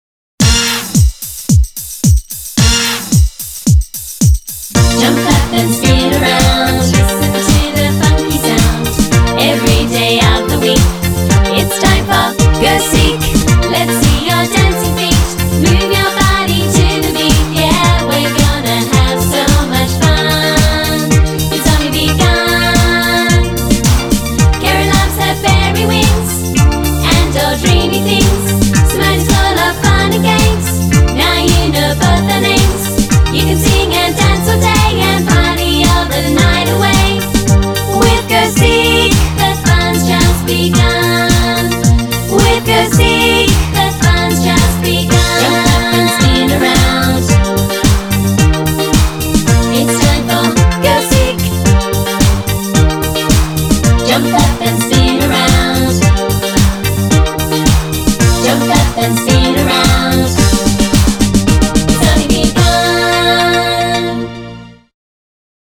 Genre: Children.